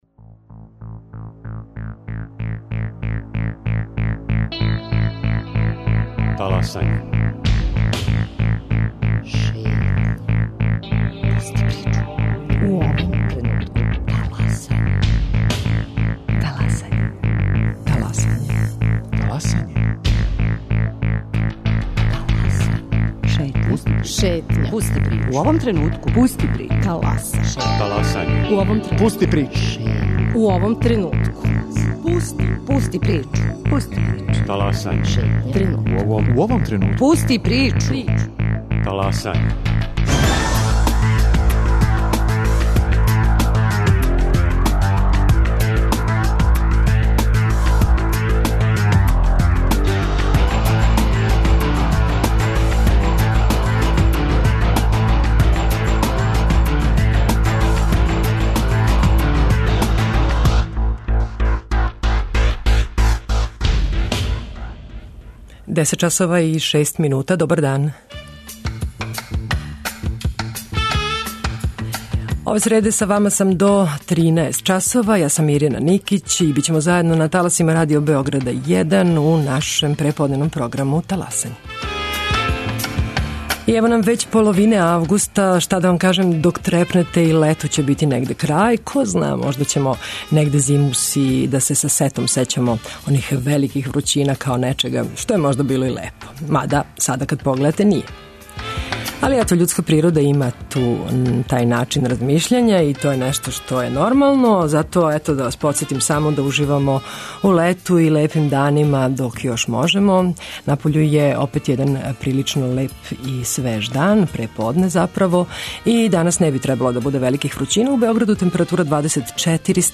У Шетњи вас водимо у Косовску Митровицу, где је наш репортер ових дана разговарао са грађанима о тек завршеним Олимпијским играма у Лондону.
Прва је о животу младих на југу Србије, њиховим очекивањима и могућностима. Разговарамо са представницима Канцеларија за младе ових градова.